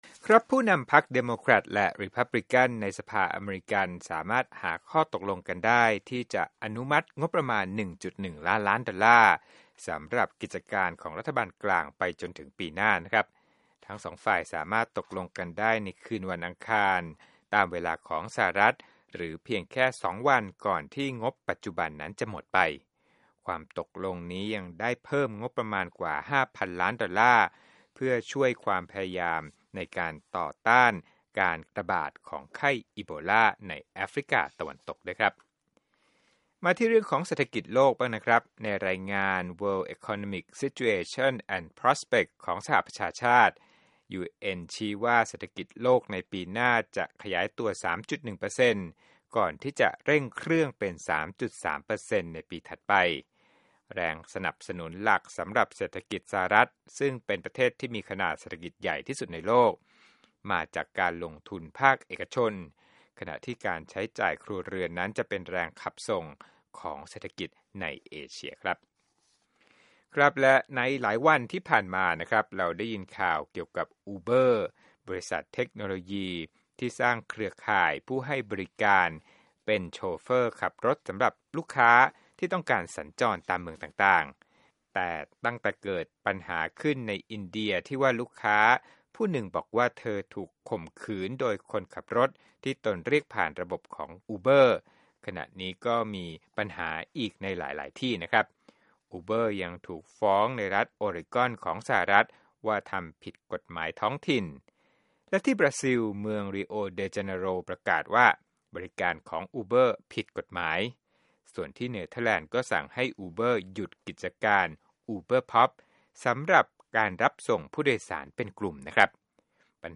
Business News
โปรดติดตามรายละเอียดจากคลิปเรื่องนี้ในรายการข่าวสดสายตรงจากวีโอเอ